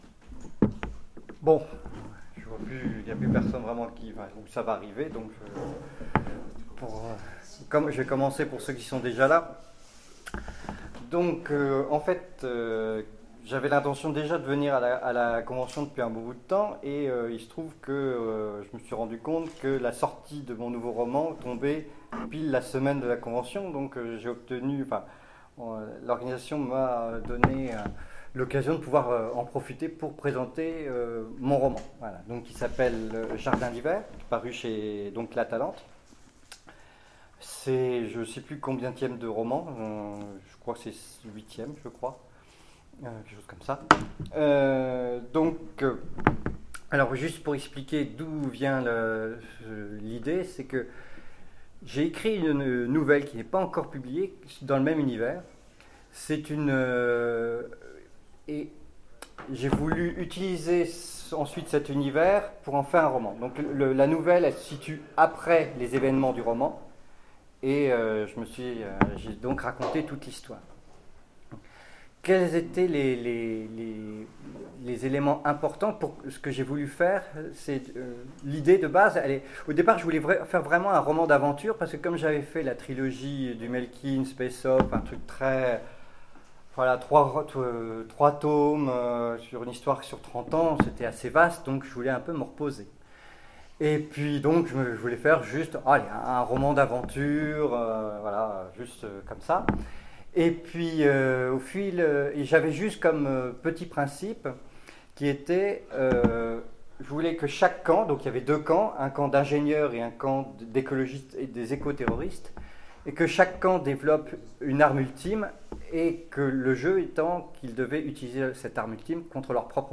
Convention SF 2016 : Conférence présentation Jardin d'hiver